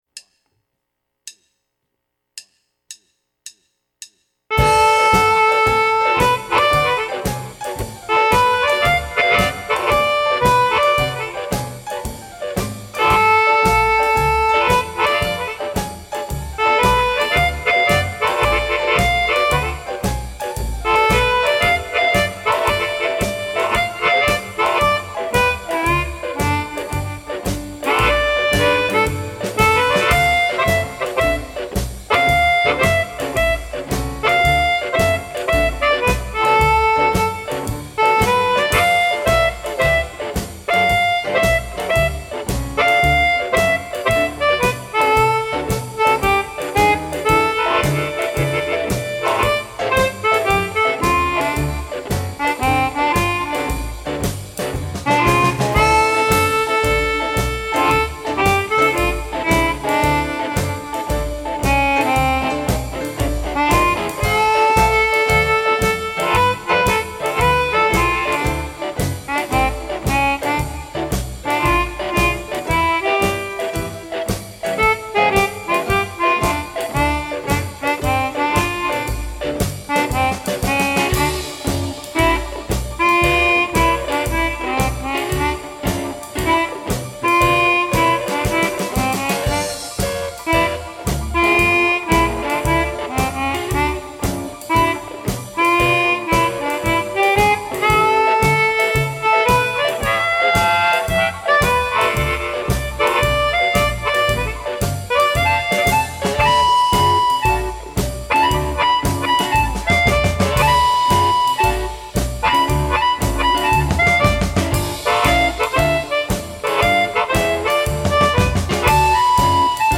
Blues chromatic is rare to hear in "student recordings"!
Swinging and catchy playing!
Nice playing ,Solid technique!
You really show control of the chromatic here. I like your tone and articulation.